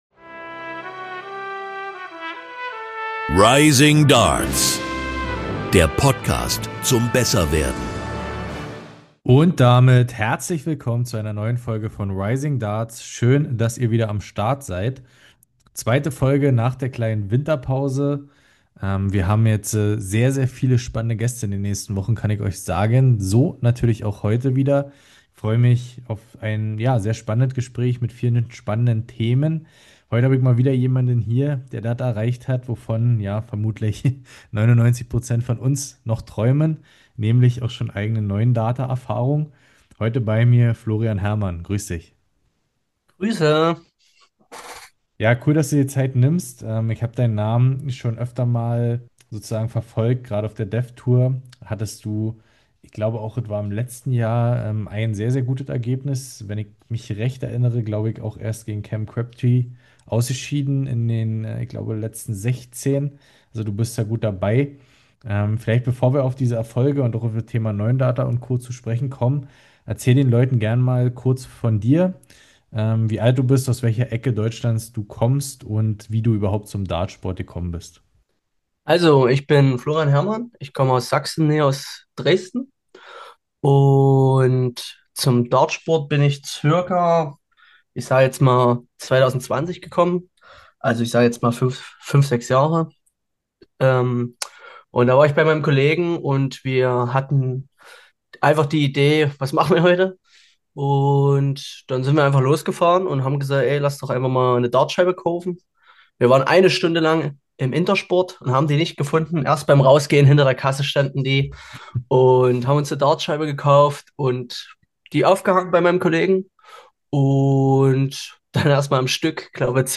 Gute Ergebnisse bei der Development Tour der PDC und sein erster 9-Darter sind Themen in unserem Gespräch.